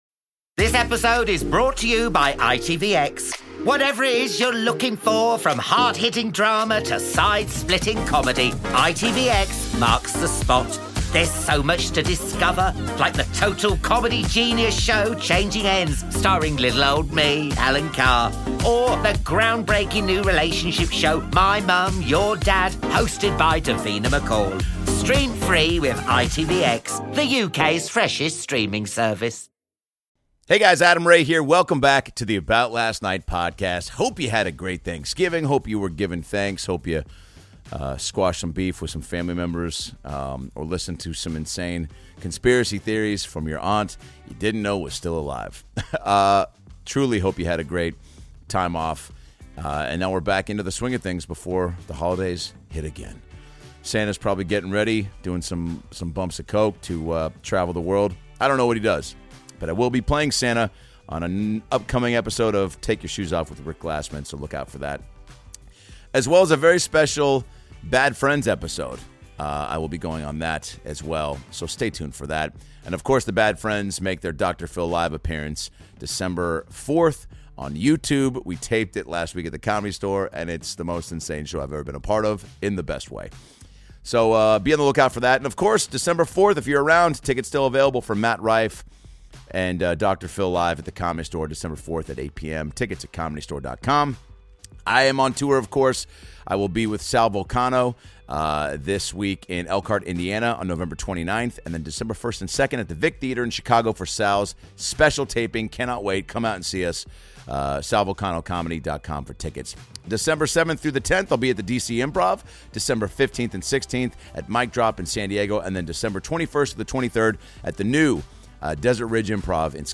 Patrick J. Adams joins Adam Ray on the About Last Night Podcast talks with Adam Ray about "Suits" resurgence and working with Meghan Markle, going to USC with Adam and his wife Troian Bellisario calls in!